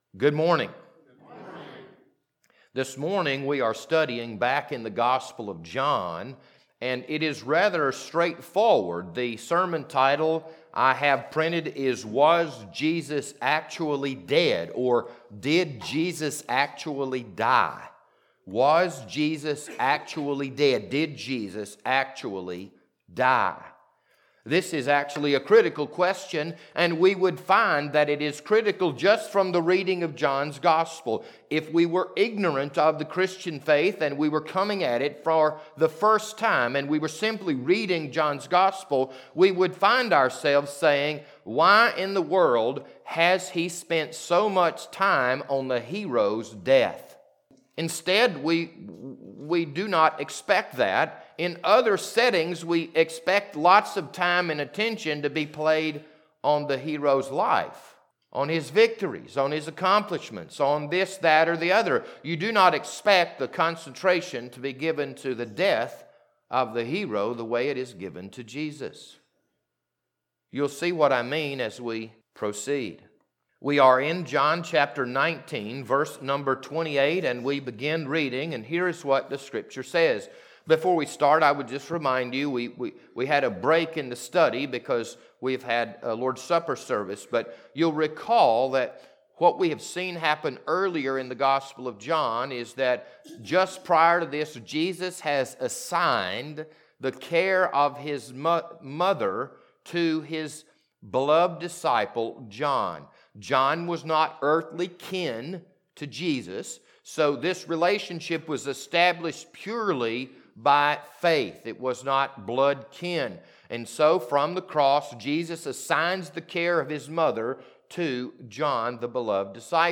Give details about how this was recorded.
This Sunday morning sermon was recorded on August 15th, 2021.